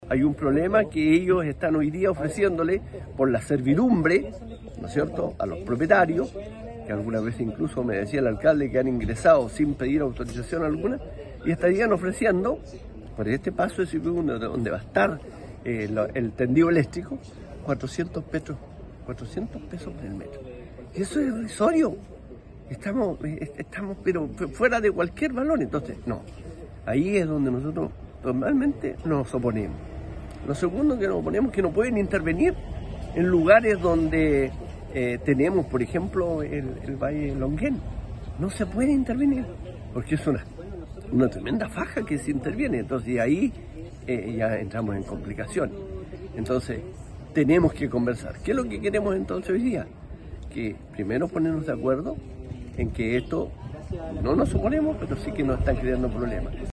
Sobre ese punto, Luis Molina, alcalde de Ninhue y presidente de las comunas del Valle Itata, indicó que “estamos de acuerdo con el proyecto Mataquito (…), pero tenemos que conversar”.